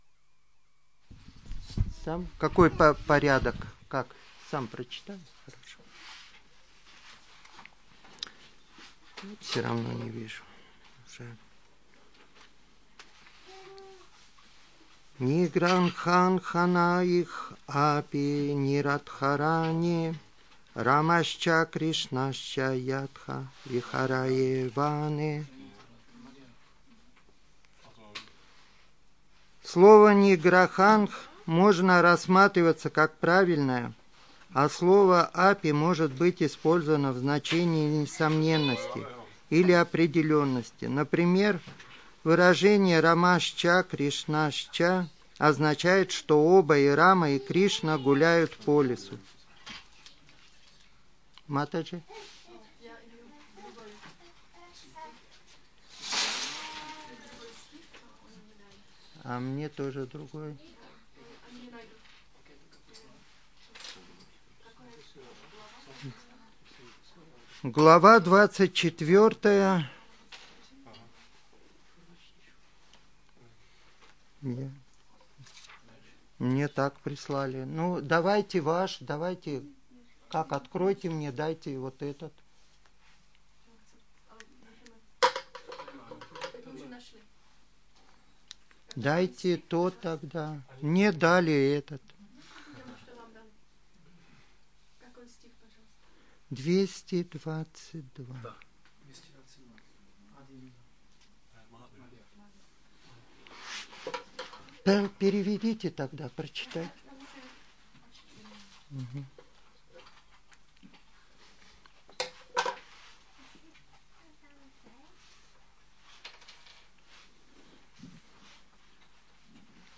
Přednáška CC-MAD-24.222